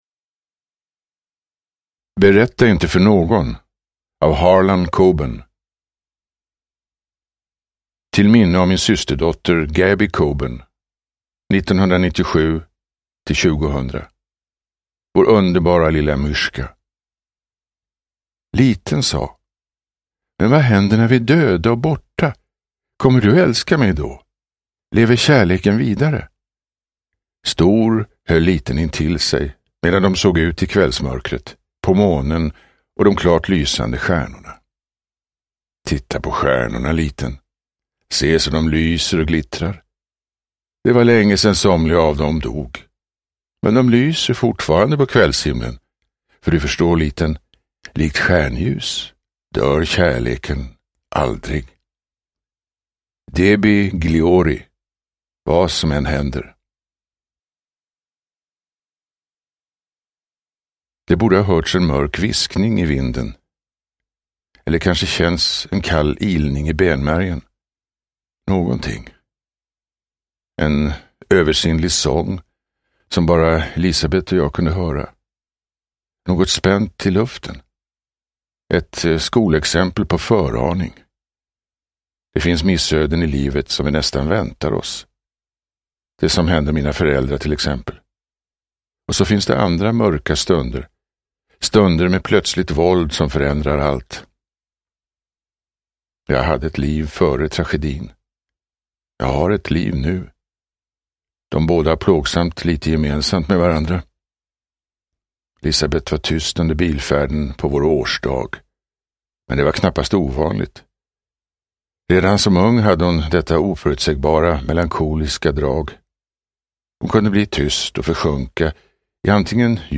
Berätta inte för någon – Ljudbok – Laddas ner
Uppläsare: Torsten Wahlund